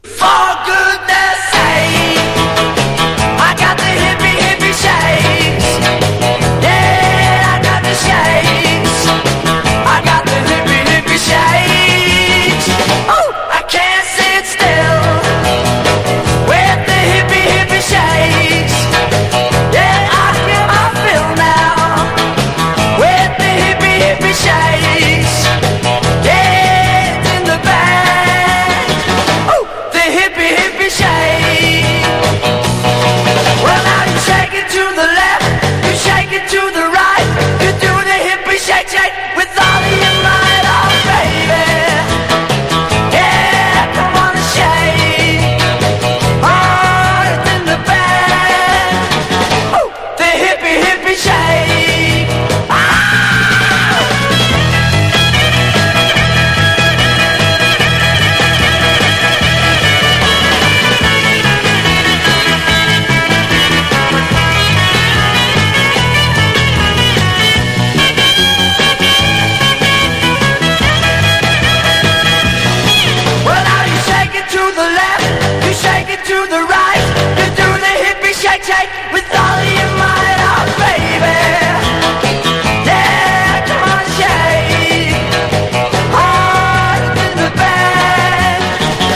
1. 60'S ROCK >
VOCAL & POPS